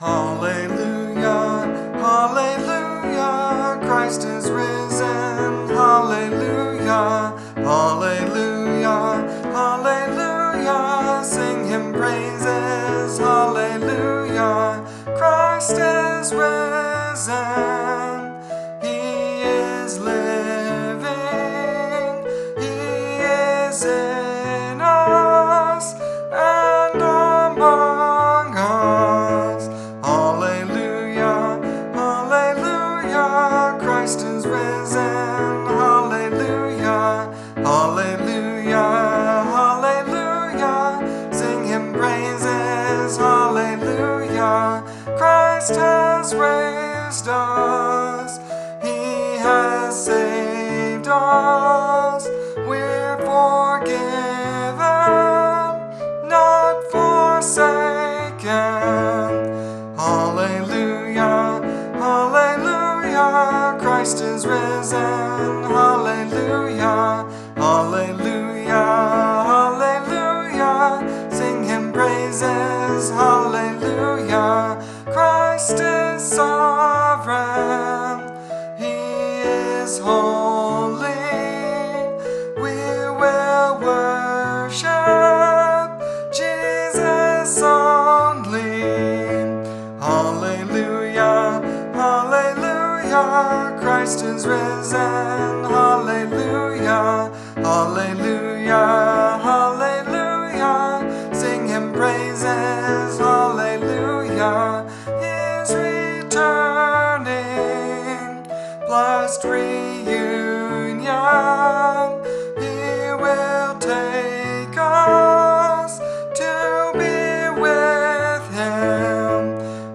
In bright A major this song reverses the typical verse/chorus structure by opening with a catchy chorus melody.
Piano Arrangement